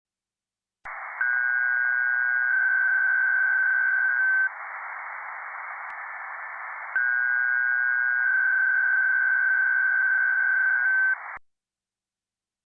bpsk.mp3